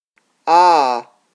Trây vowel /ää/